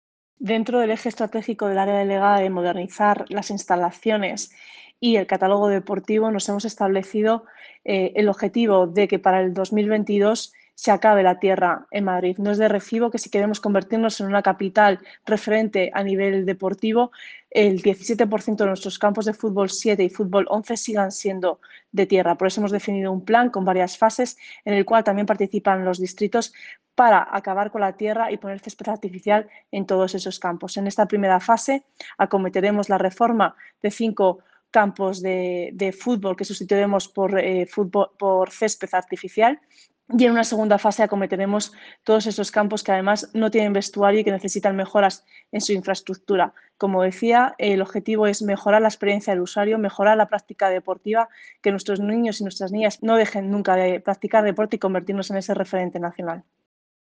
Nueva ventana:La concejala delegada de Deporte, Sofía Miranda, explica el compromiso del Gobierno municipal para acabar con los campos de tierra en la ciudad de Madrid